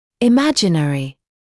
[ɪ’mæʤɪnərɪ][и’мэджинэри]воображаемый; мнимый